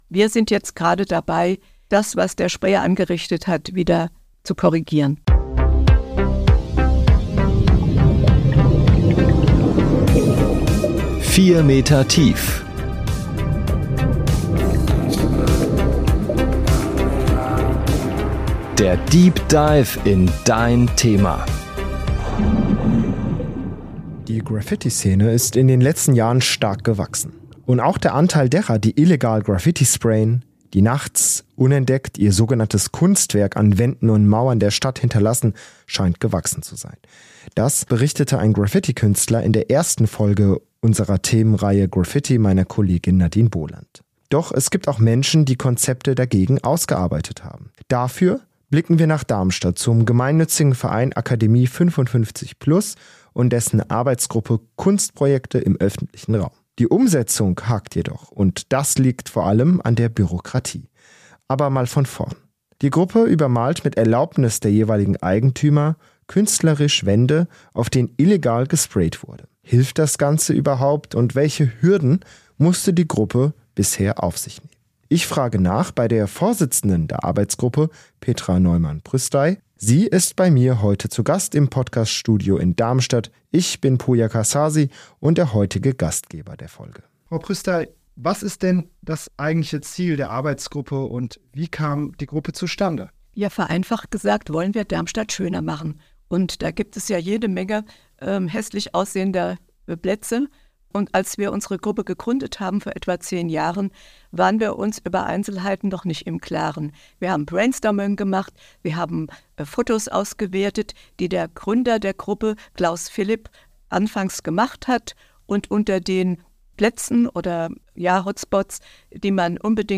zu Gast im Podcast-Studio